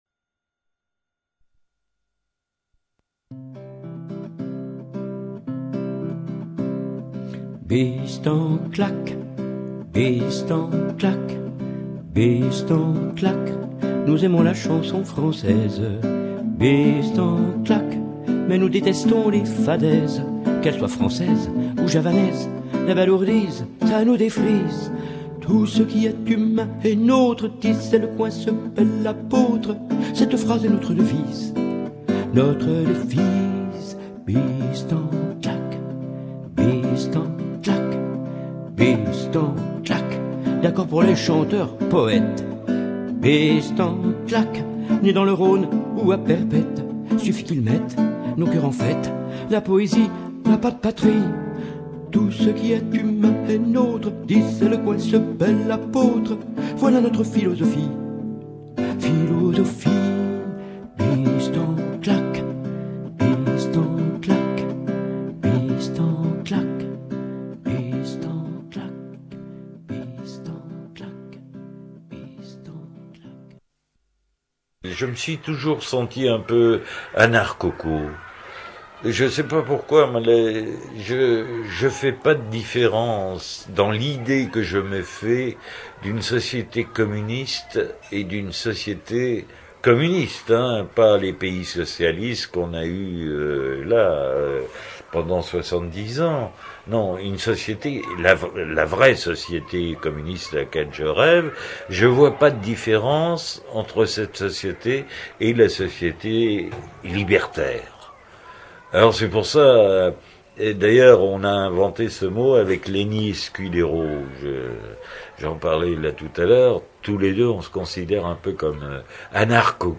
ceci est la 2 ème émission réalisée à partir de l’entretien que Claude VINCI à bien voulu accorder à l’émission en août 2003